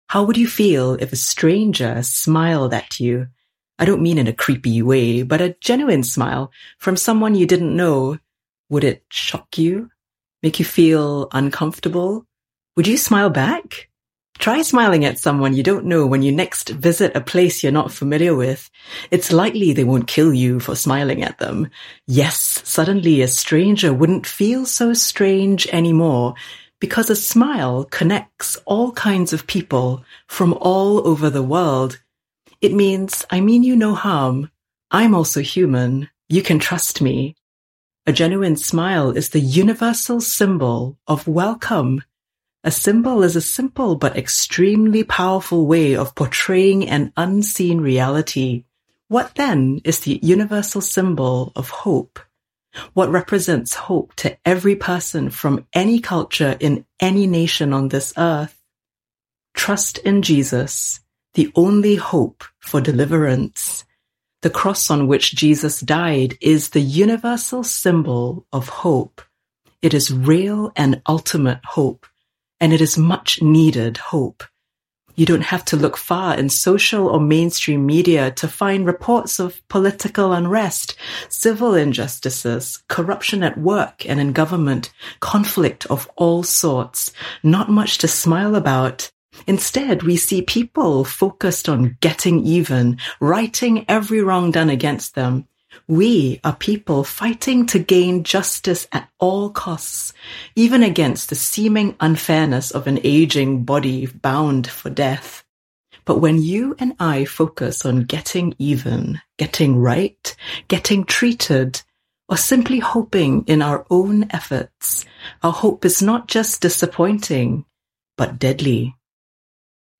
Audio Teaching